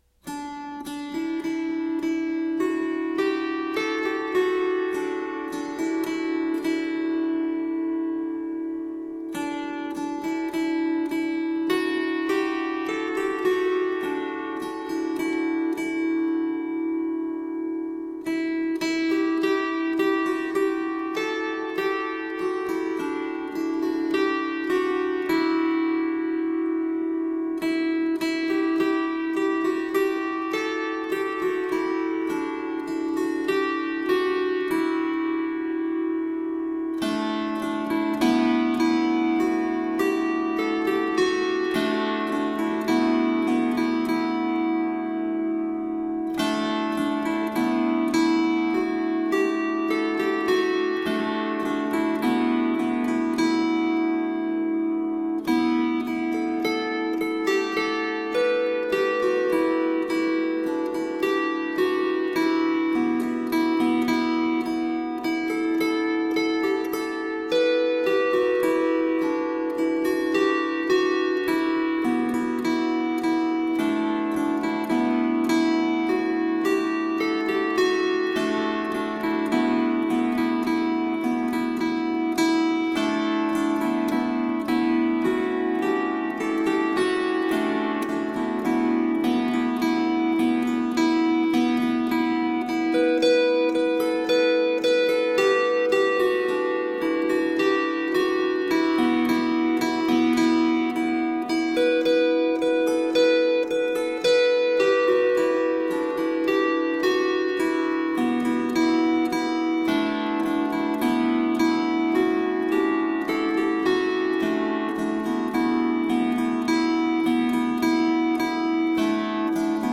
Medieval and middle eastern music.
medieval Irish